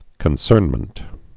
(kən-sûrnmənt)